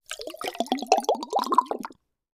Звук наполняющейся водой бутылки